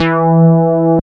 69.06 BASS.wav